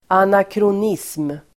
Uttal: [anakron'is:m]